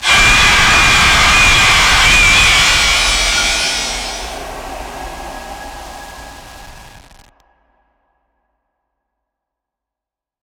file) 195 KB sad 1